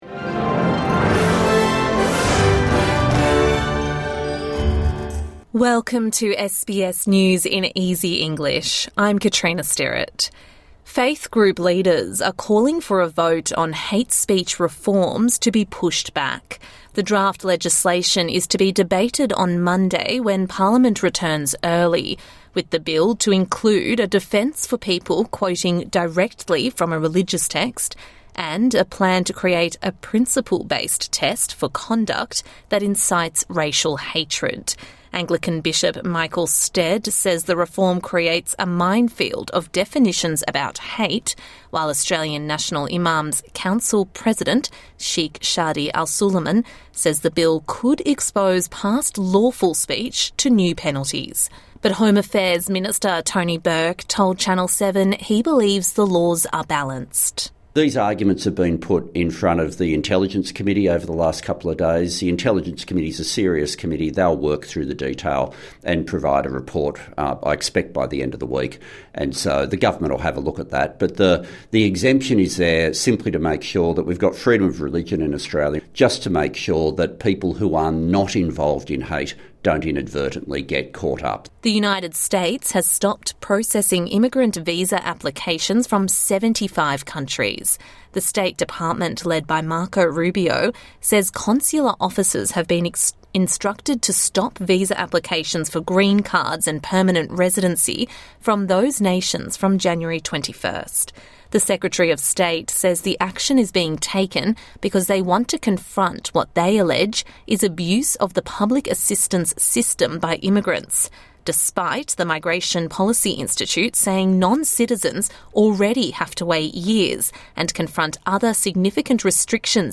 A daily 5 minute news bulletin for English learners and people with a disability.